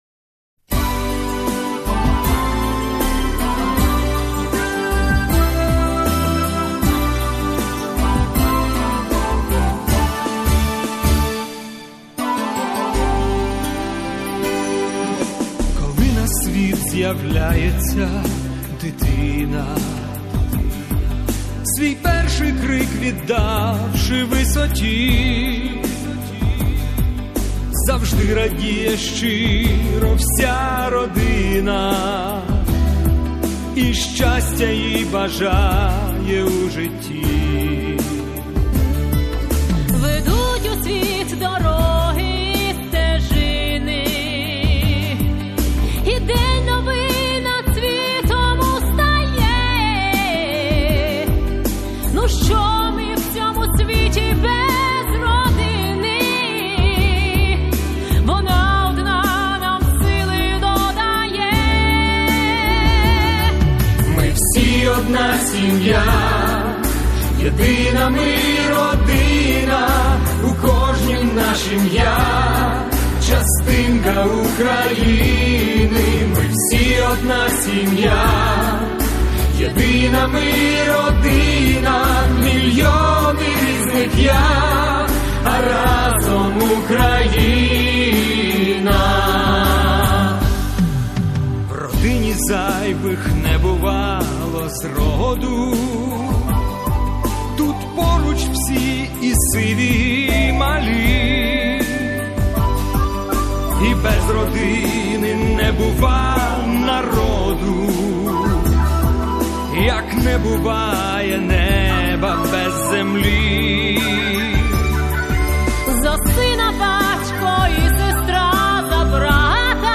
Всі мінусовки жанру Ballad
Плюсовий запис